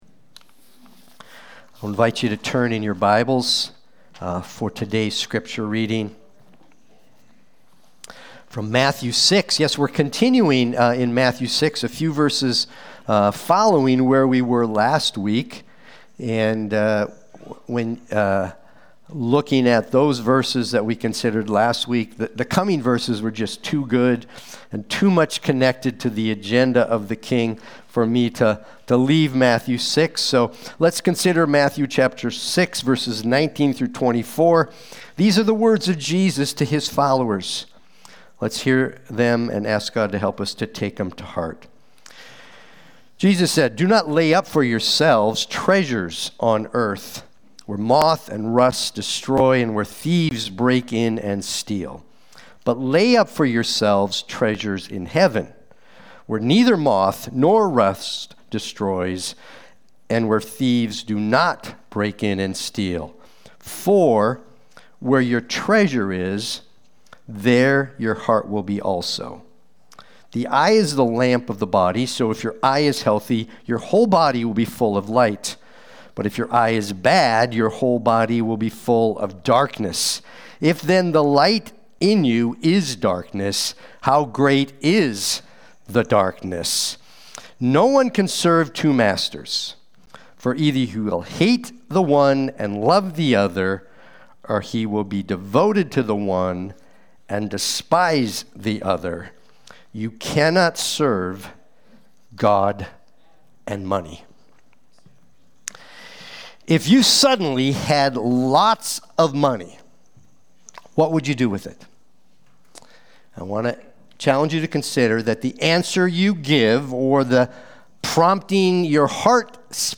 Watch the replay or listen to the sermon.